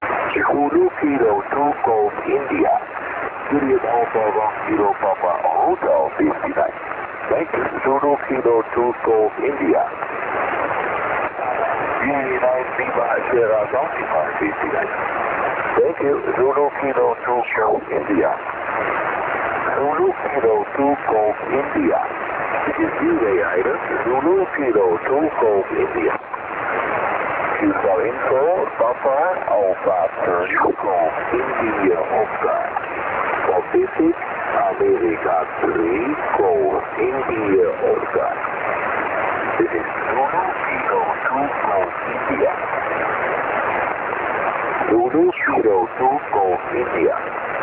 Operating on 20, 17, 15, 12 and 10M in only SSB
Equipment: Kenwood TS-50S 100W and 2 x 20M Doublet.